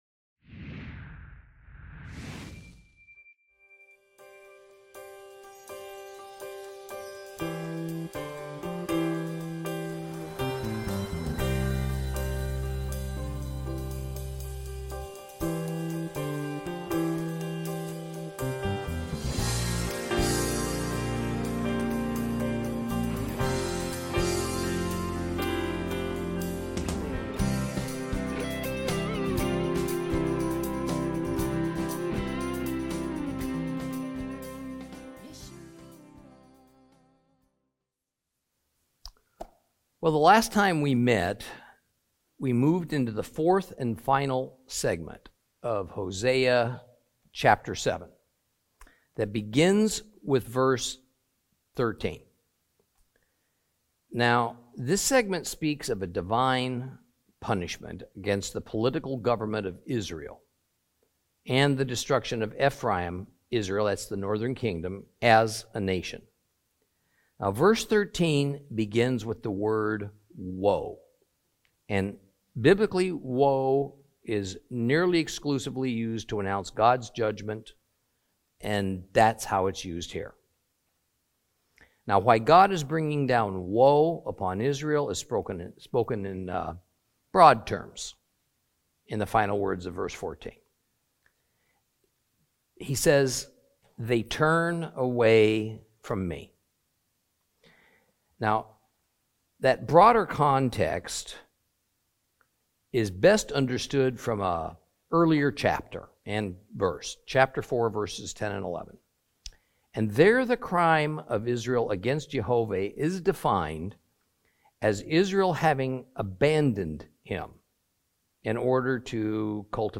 Teaching from the book of Hosea, Lesson 13 Chapters 7 and 8.